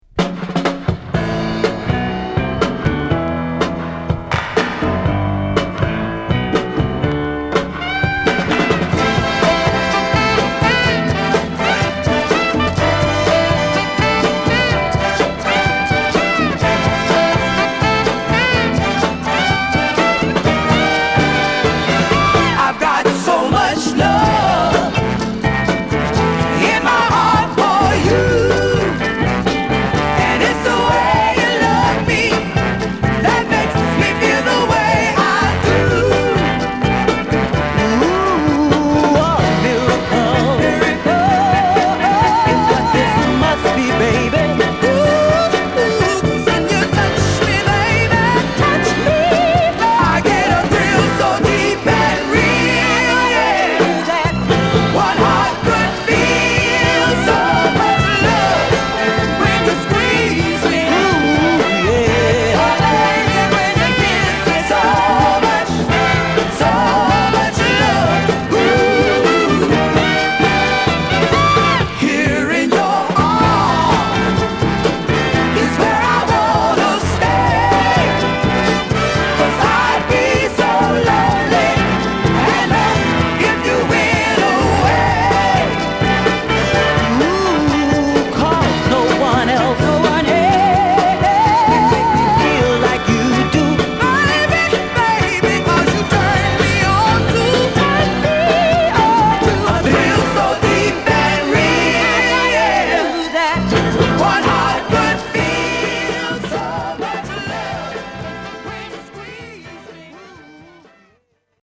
終盤にはブレイクポイントも有り。